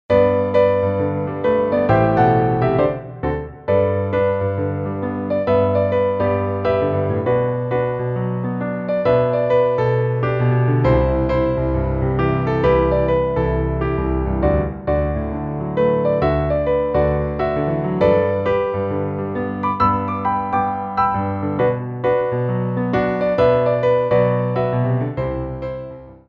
33 Tracks for Ballet Class.
Dégagés
4/4 (8x8)